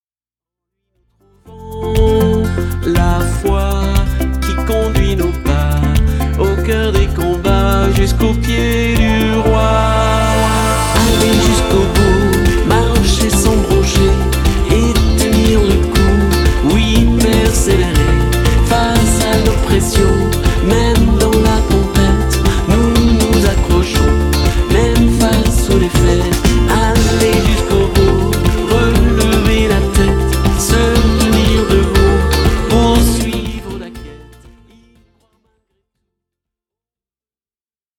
un single très dansant !